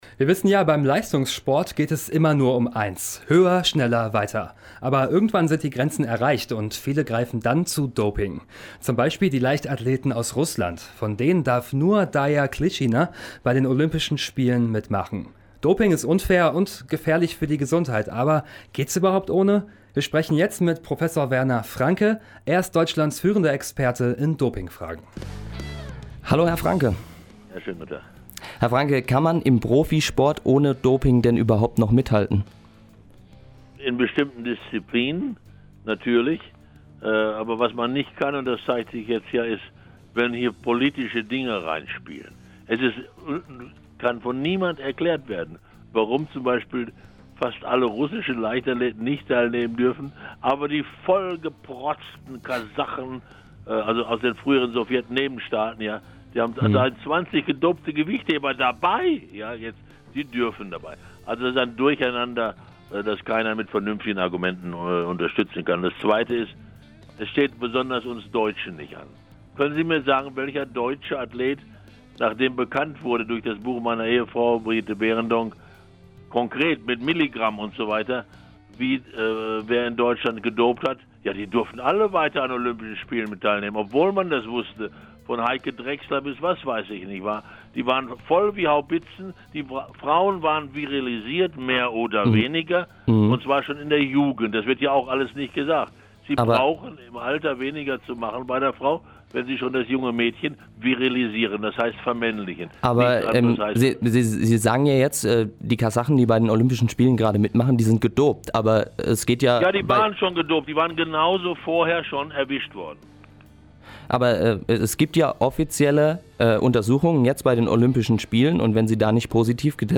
Doping-Experte Werner Franke im Interview
Wir haben mit Prof. Werner Franke gesprochen.